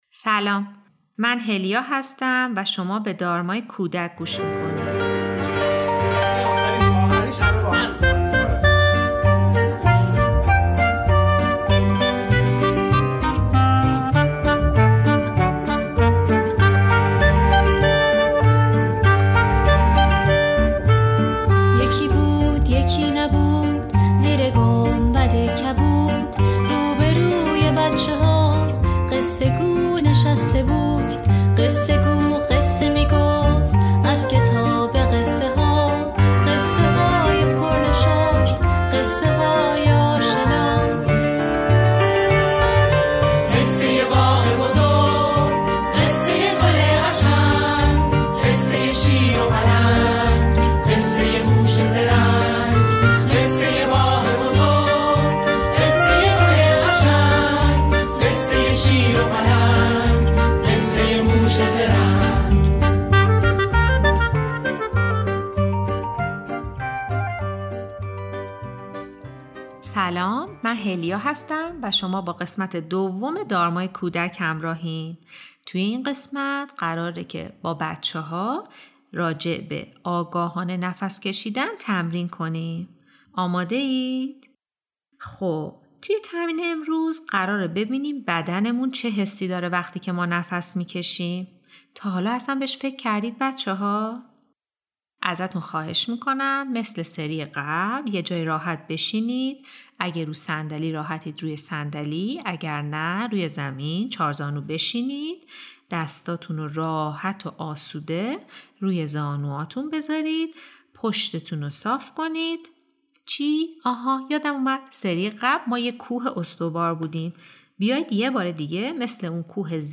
مدیتیشن: تنفس آگاهانه